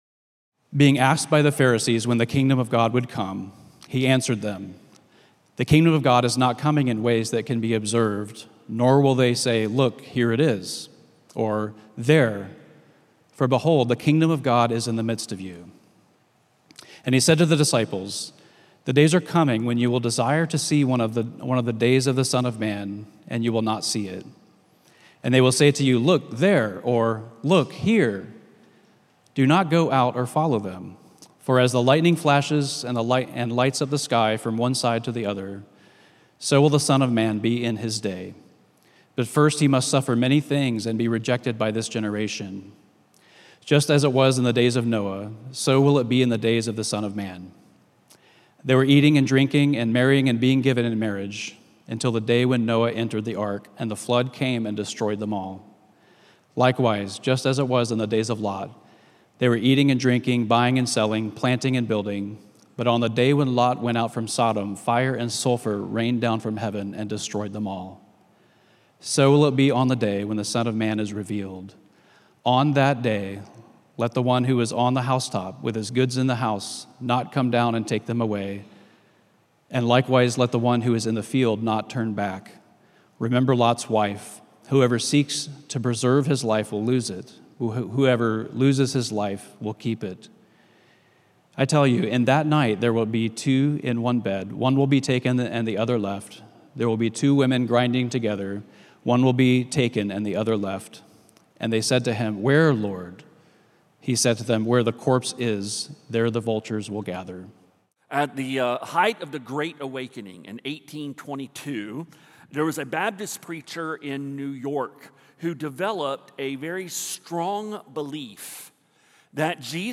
A message from the series "Holy God Holy People."